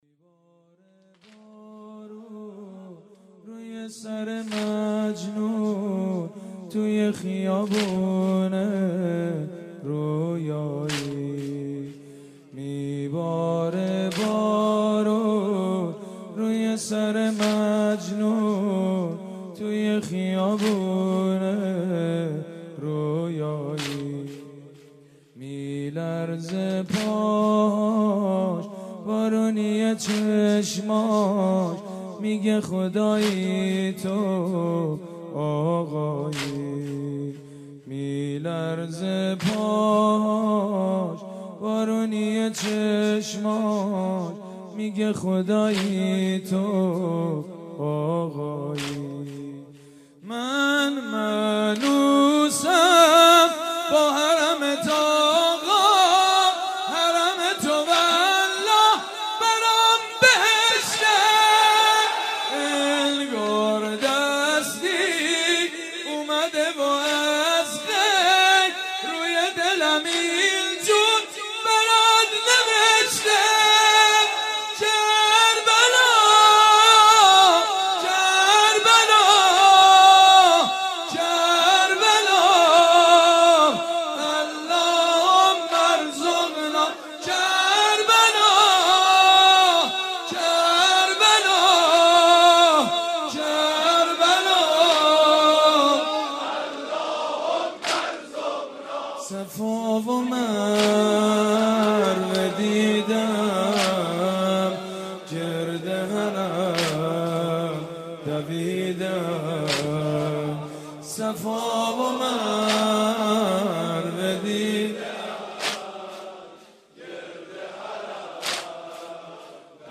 مداحی های پیاده روی اربعین